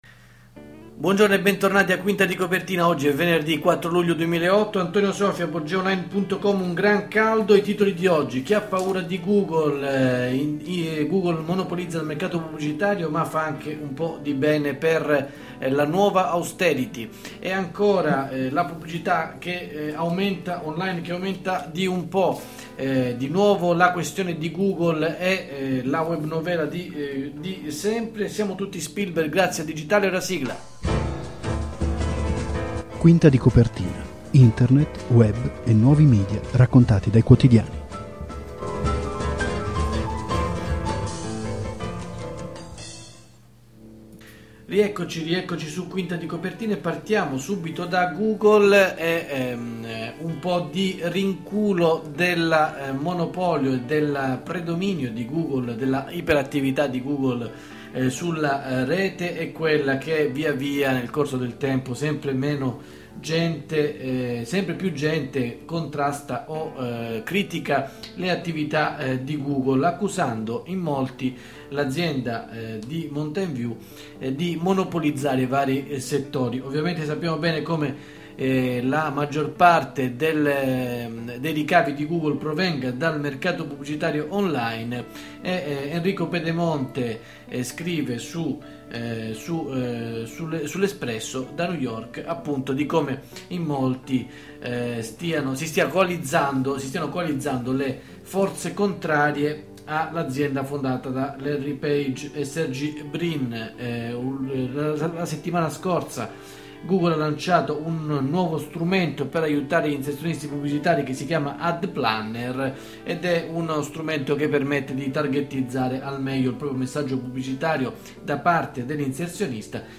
In sottofondo:Intro – A New World, di Camillo Miller